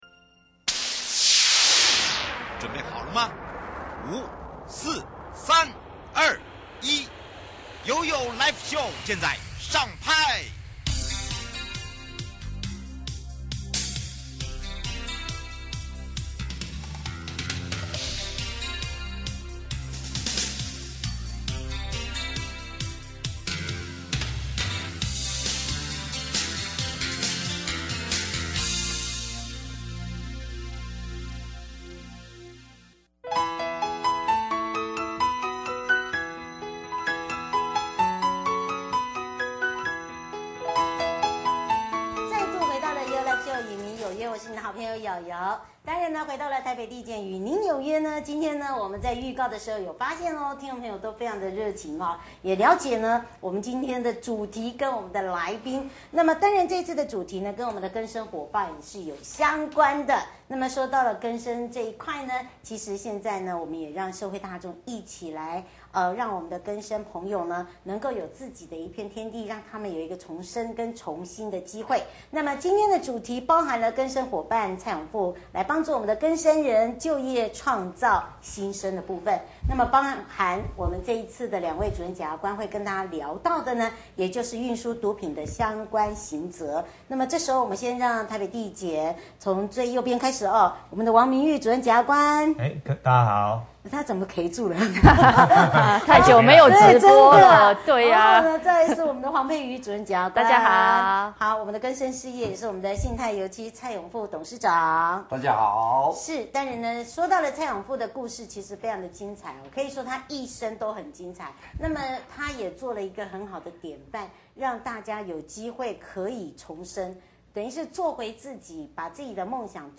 與民有約直播